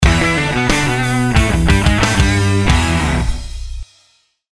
• Качество: 320, Stereo
громкие
саундтреки
электрогитара
ударные
барабаны